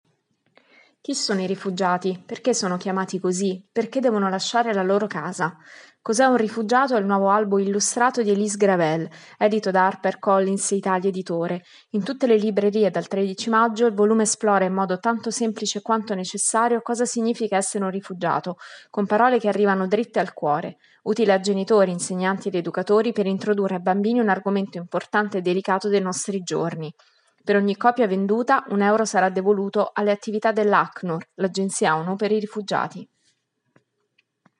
servizio-rifugiato.mp3